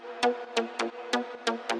美国，加利福尼亚州 " 窗户的振动变慢了
描述：窗户的振动放慢了。 在莫哈韦沙漠（加利福尼亚）录制的
声道立体声